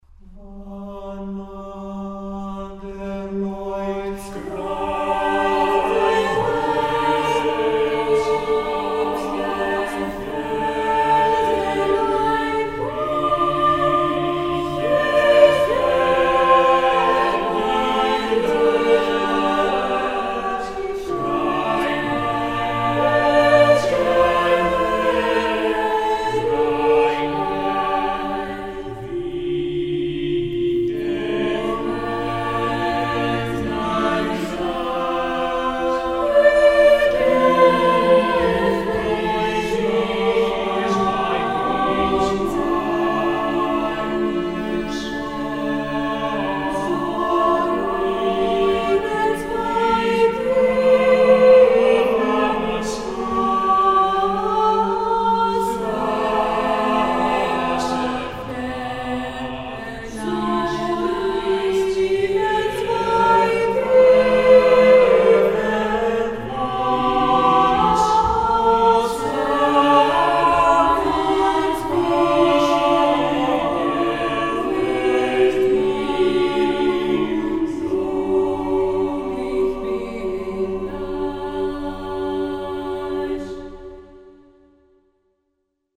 Ηχογράφηση από την πρόβα της Τρίτης 2 Μαΐου 2006.
Με ισοστάθμιση, αντήχηση και στερεοφωνία
eq = Equalizer, rev = reverb